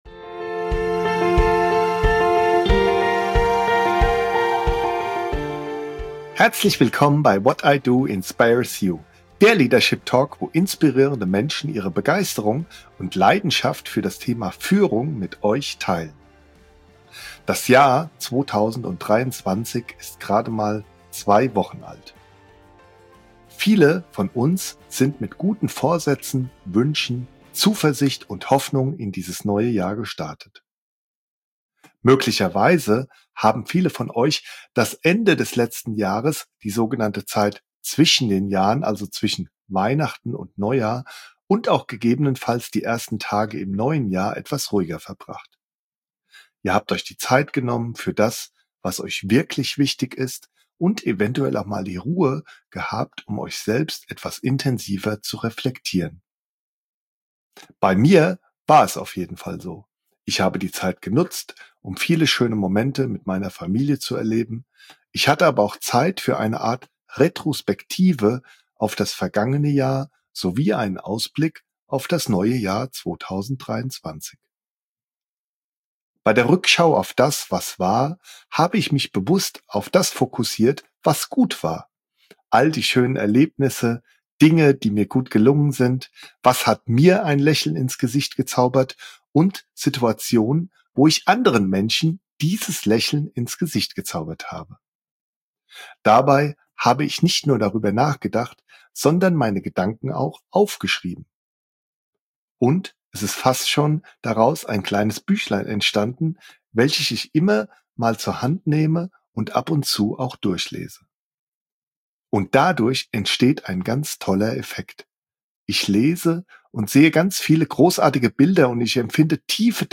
whatidoinspiresyou ist der Leadership-Talk, der euch ein Lächeln ins Gesicht zaubert, wenn ihr an das Thema „Führung“ denkt.